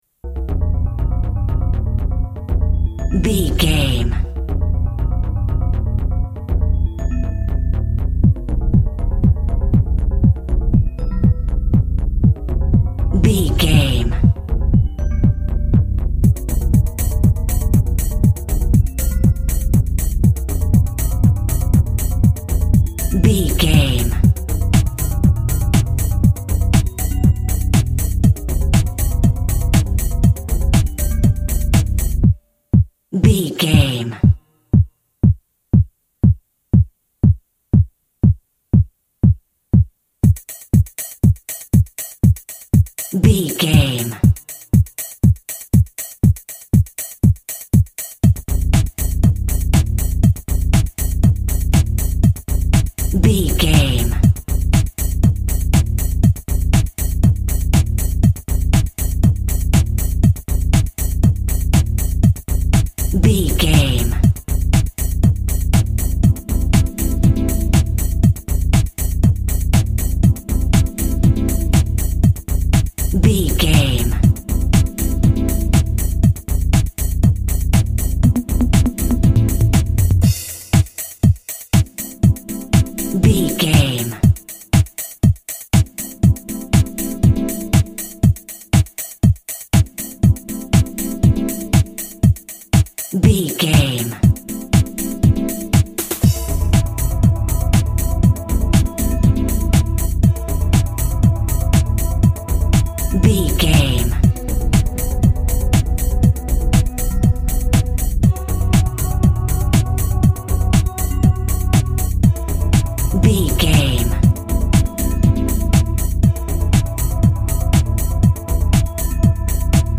Groovy Electronic Music.
Atonal
groovy
uplifting
futuristic
energetic
drums
synthesiser
drum machine
techno
trance
synth lead
synth bass
Synth Pads